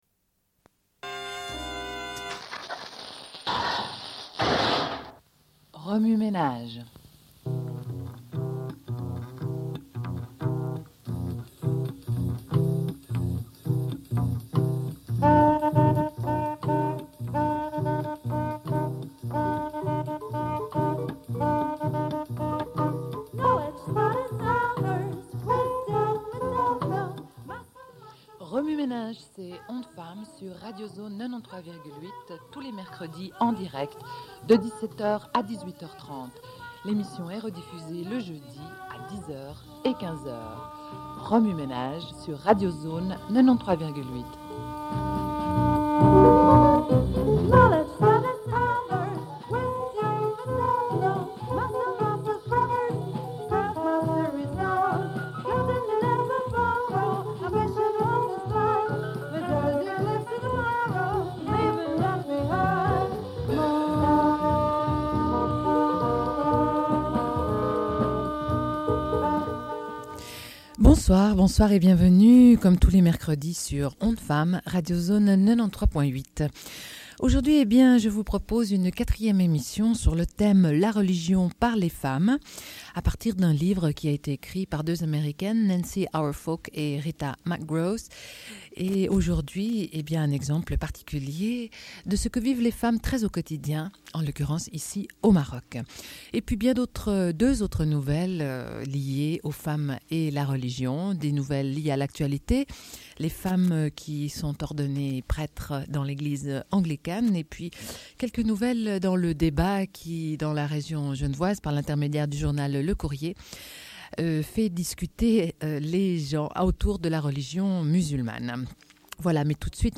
Une cassette audio, face A